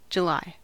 Ääntäminen
Ääntäminen Tuntematon aksentti: IPA: /ˈhei.næˌkuː/ Haettu sana löytyi näillä lähdekielillä: suomi Käännös Ääninäyte Erisnimet 1.